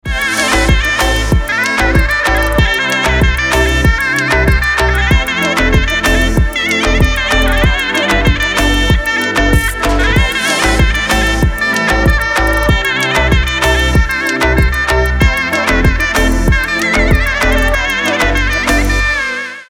• Качество: 320, Stereo
зажигательные
без слов
восточные
арабские
Яркая восточная музыка